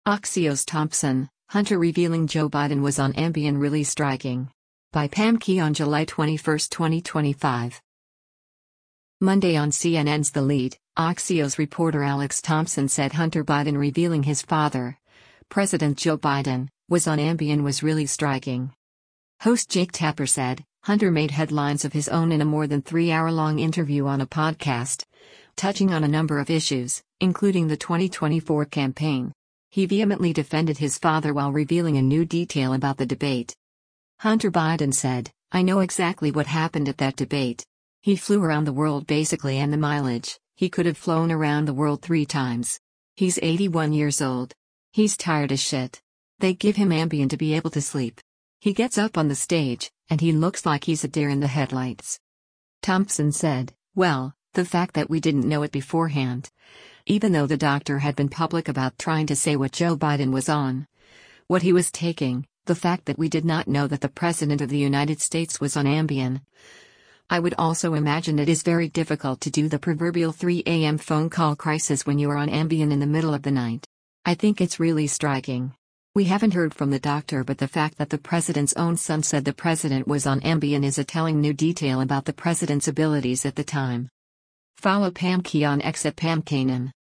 Host Jake Tapper said, “Hunter made headlines of his own in a more than three-hour-long interview on a podcast, touching on a number of issues, including the 2024 campaign. He vehemently defended his father while revealing a new detail about the debate.”